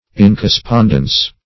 Incorrespondence \In*cor`re*spond"ence\
incorrespondence.mp3